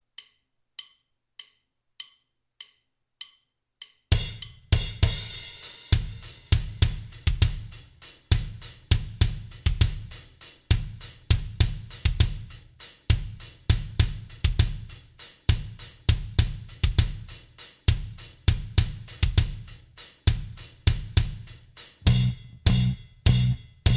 End Cut Down Rock 5:35 Buy £1.50